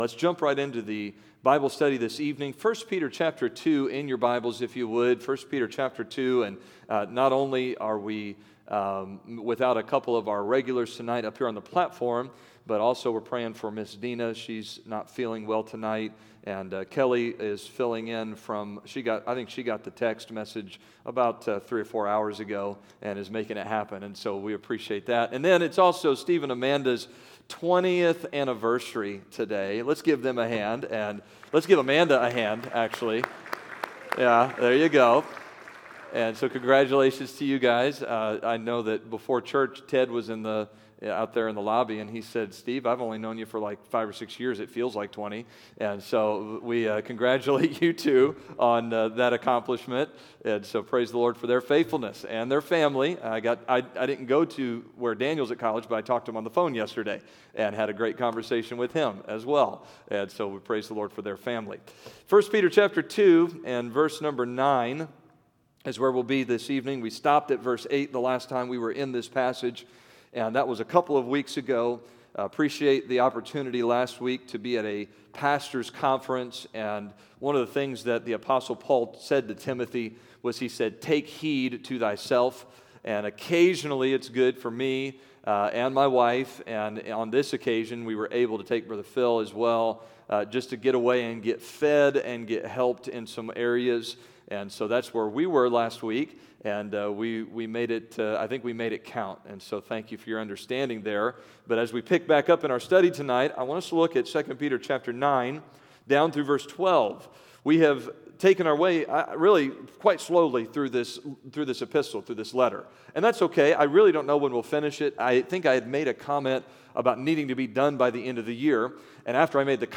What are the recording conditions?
September 2022 A Study in 1 Peter Wednesday Evening Scripture: 1 Peter 2: 9-12 Download: Audio